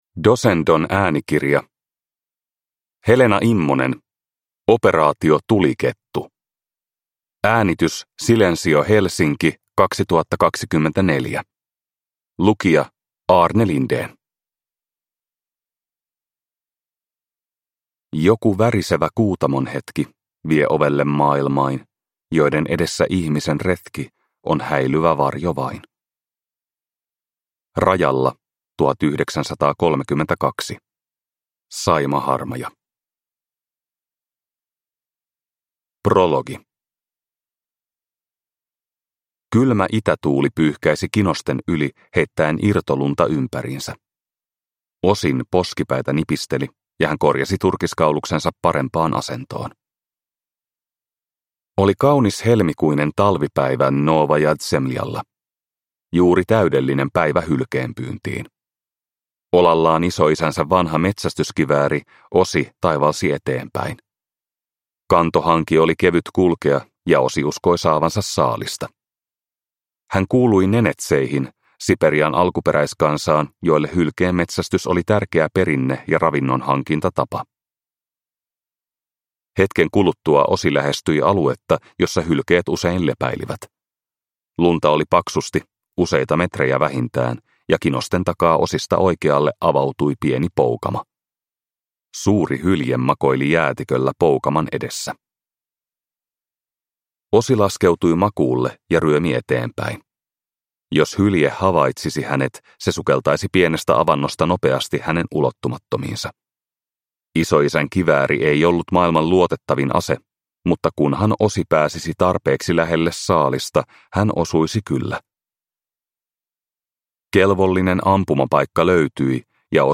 Operaatio Tulikettu (ljudbok) av Helena Immonen